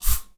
spray_bottle_06.wav